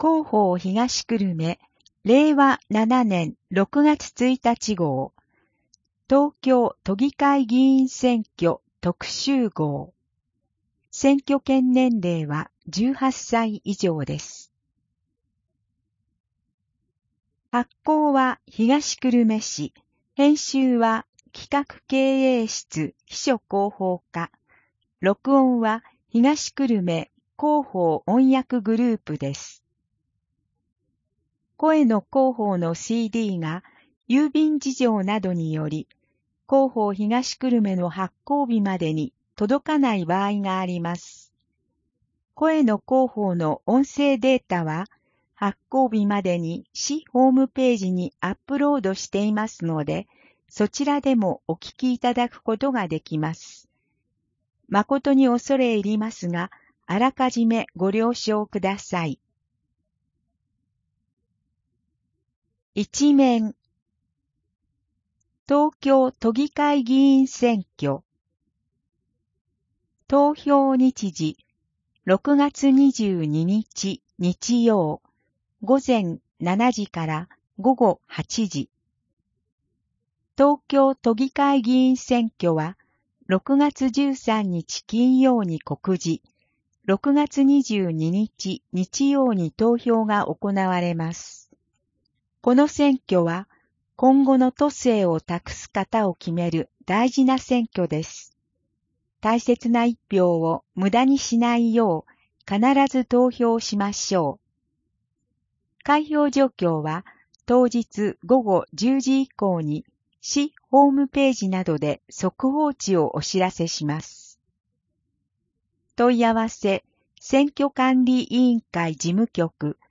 声の広報（令和7年6月1日号選挙特集号）